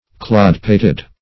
Clodpated \Clod"pat`ed\, a.